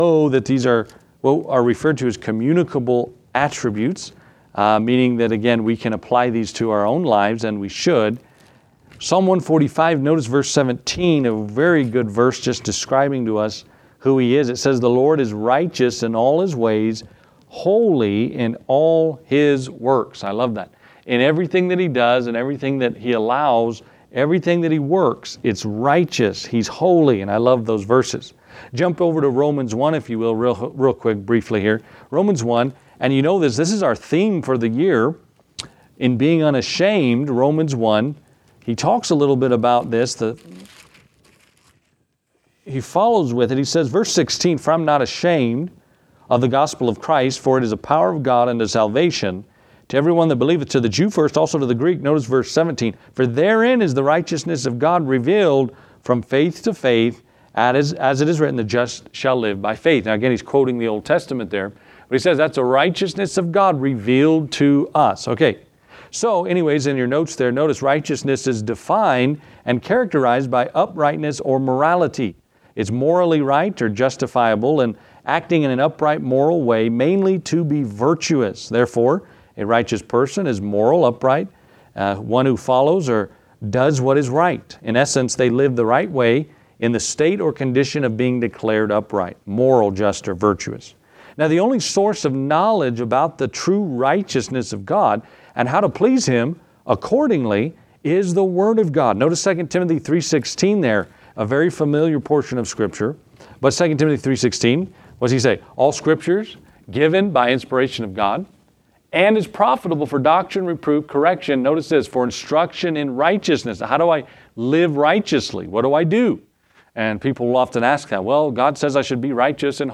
preaches on the moral attribute of God’s righteousness beginning in Psalm 145:17 and Romans 1:16-17.